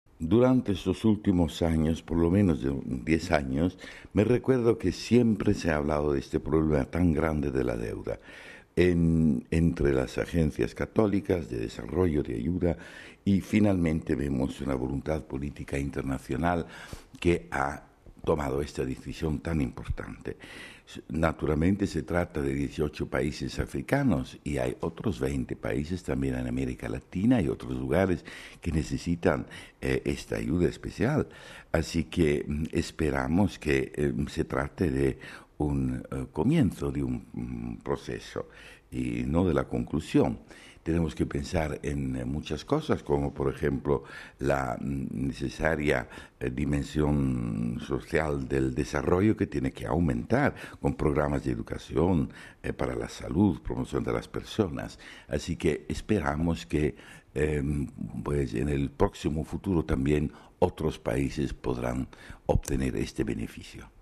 Entrevista al secretario del Pontificio Consejo Cor Unum sobre la decisión de la cancelación de la deuda a varios países en vías de desarrollo